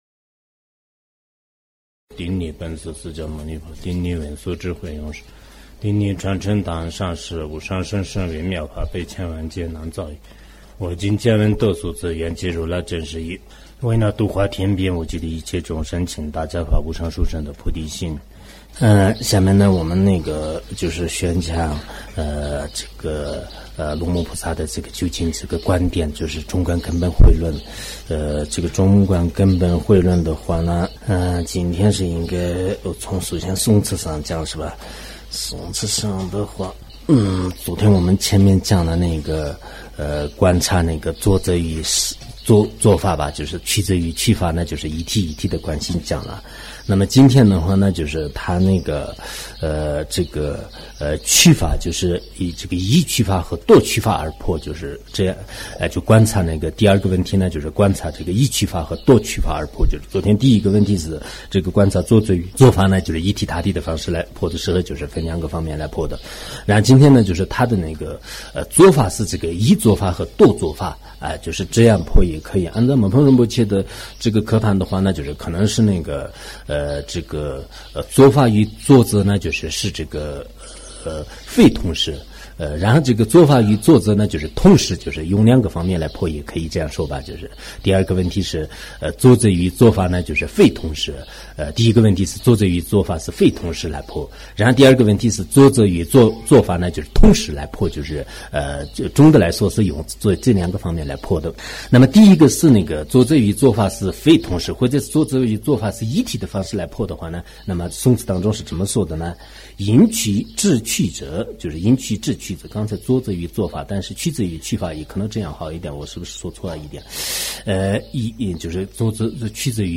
中论讲解 龙树菩萨 ·造论 麦彭仁波切 · 注释 索达吉堪布 ·译讲 顶礼本师释迦